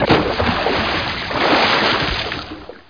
1 channel
splash.mp3